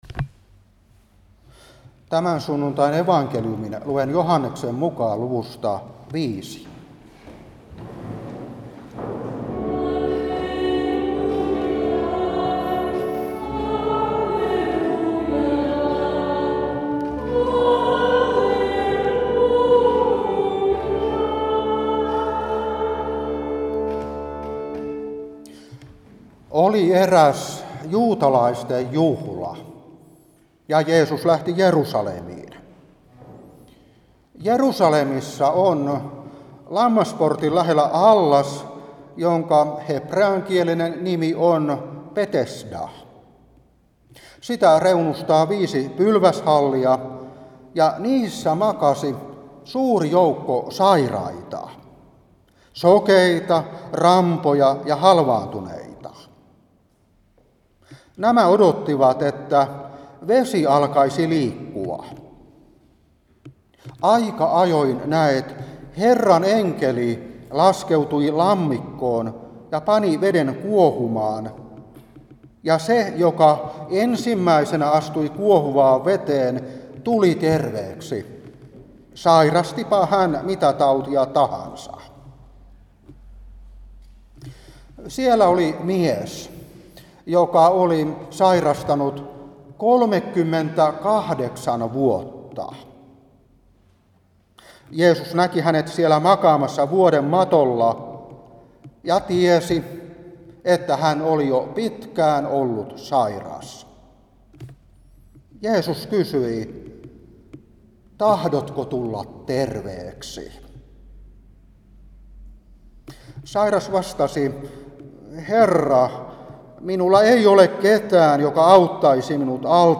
Saarna 2023-9.